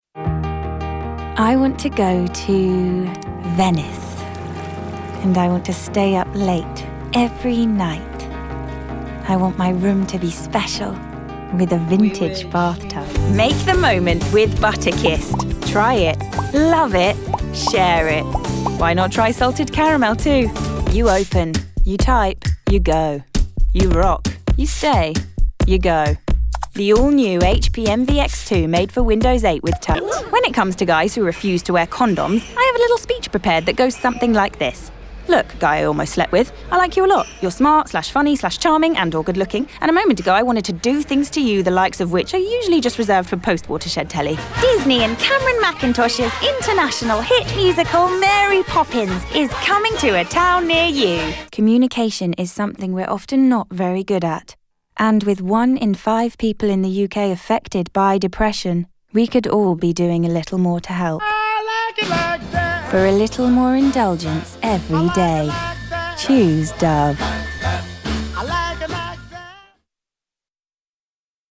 Commercial Montage